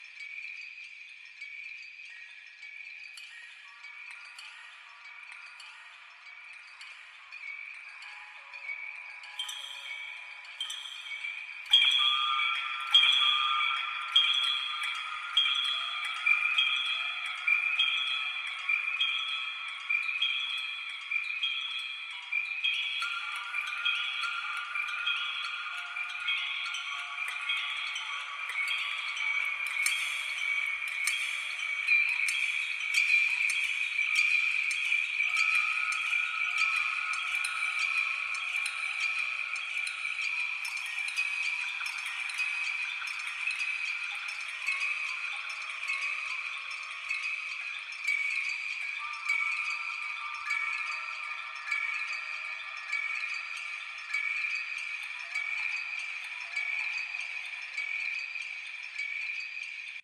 should be correct audio levels.